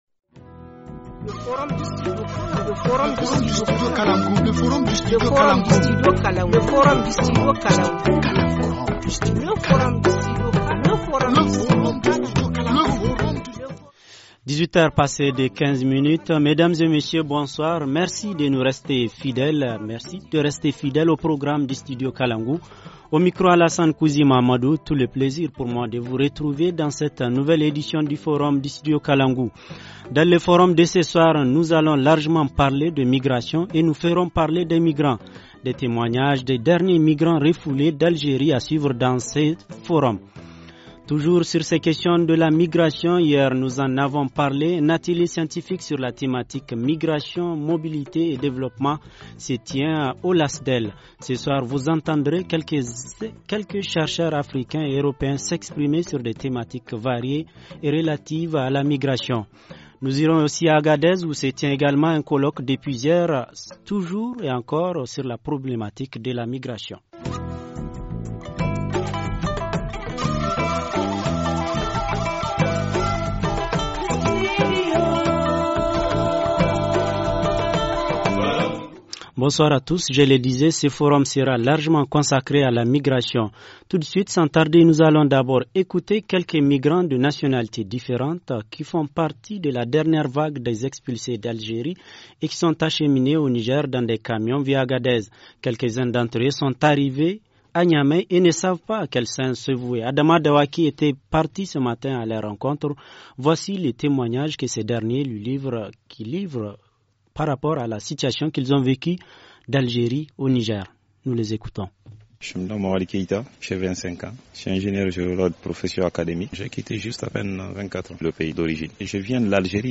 – Témoignage de quelques migrants expulsé d’Algérie :